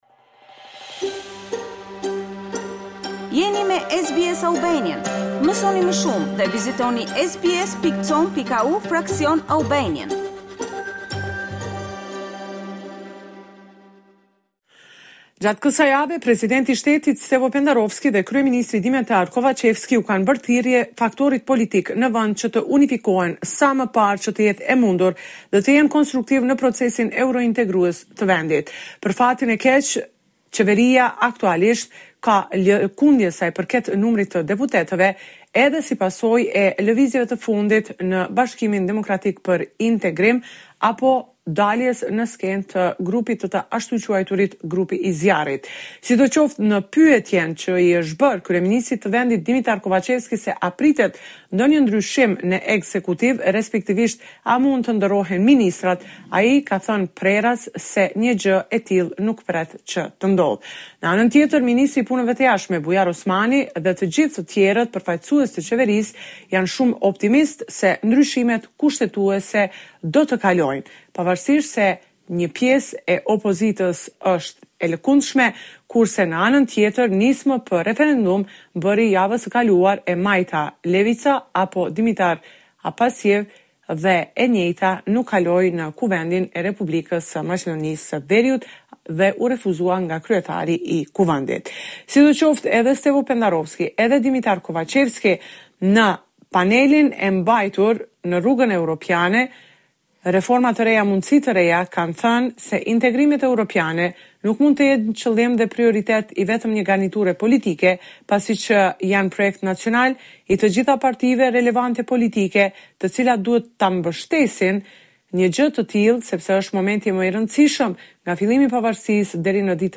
This is a report summarizing the latest developments in news and current affairs in North Macedonia.